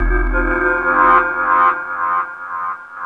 SFX61   02-R.wav